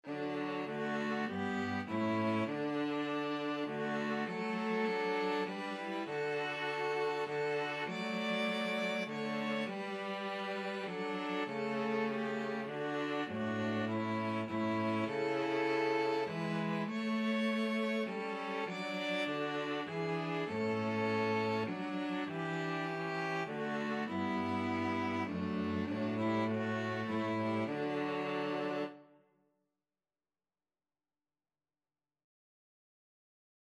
Christian Christian String trio Sheet Music When I Survey the Wondrous Cross (rockingham)
ViolinViolaCello
D major (Sounding Pitch) (View more D major Music for String trio )
3/4 (View more 3/4 Music)
String trio  (View more Easy String trio Music)
Classical (View more Classical String trio Music)